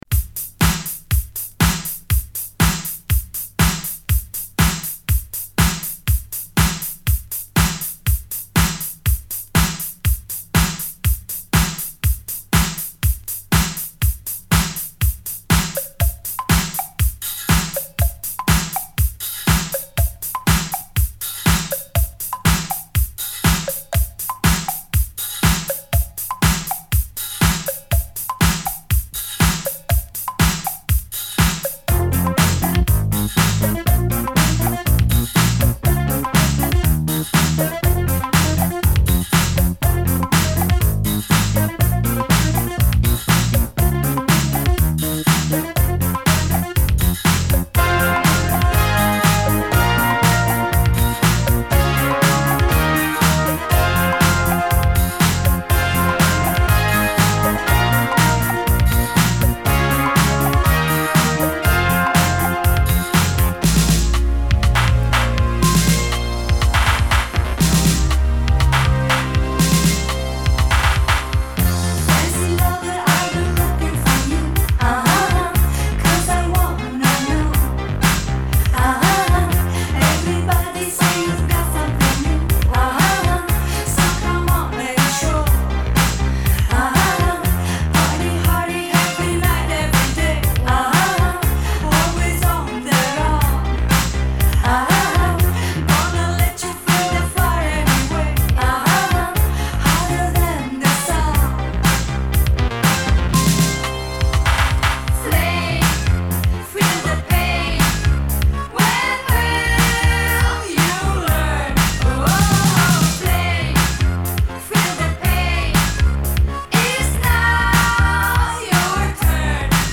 Disco Italo Disco